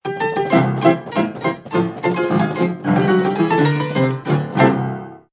Piano_Tuna.ogg